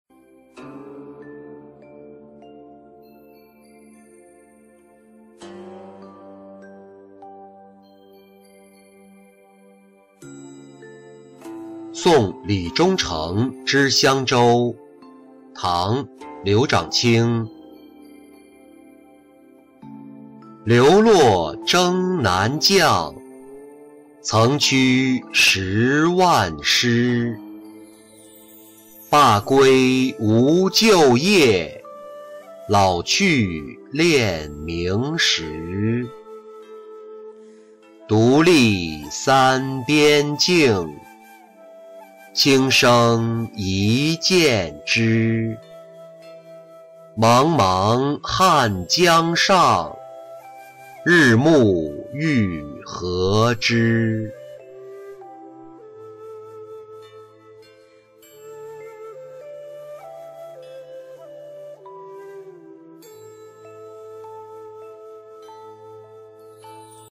送李中丞之襄州-音频朗读